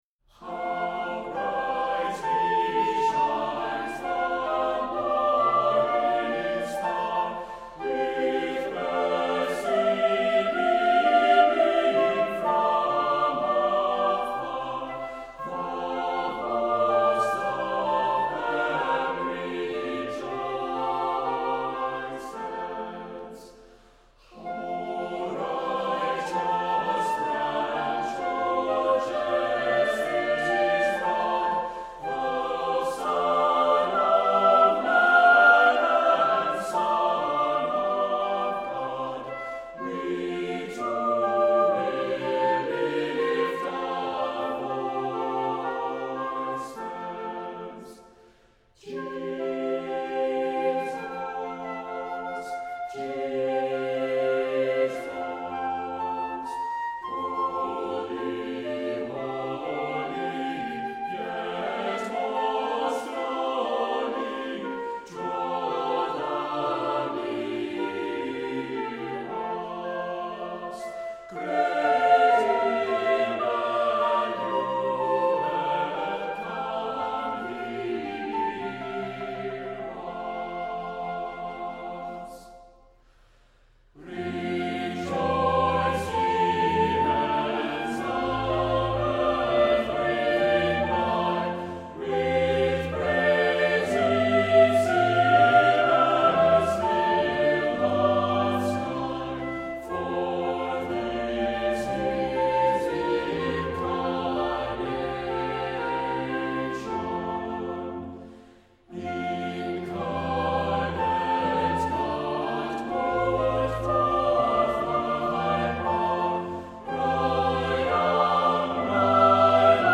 Accompaniment:      A Cappella, Flute;Violin
Music Category:      Early Music
SATB voices with violin or flute.